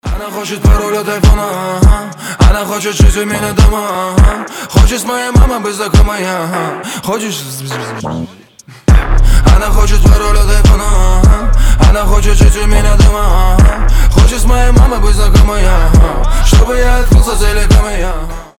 • Качество: 320, Stereo
ритмичные
басы
рэп-дуэт из Грозного